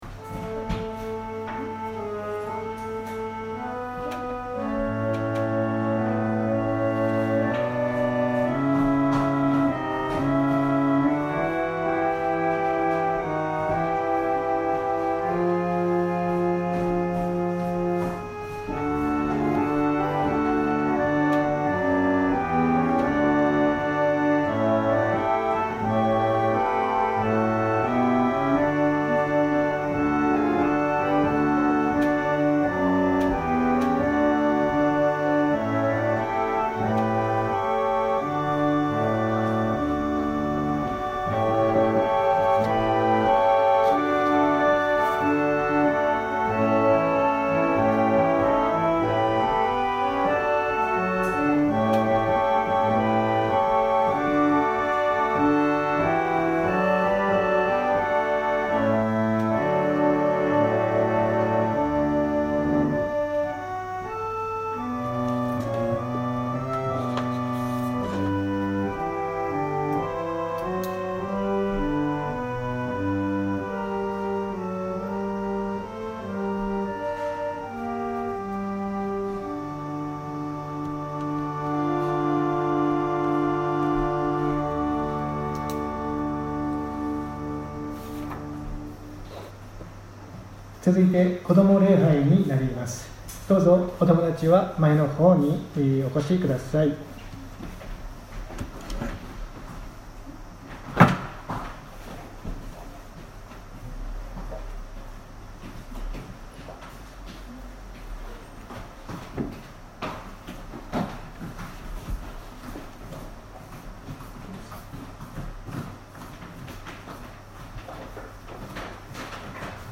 千間台教会。説教アーカイブ。
私たちは毎週日曜日10時20分から12時まで神様に祈りと感謝をささげる礼拝を開いています。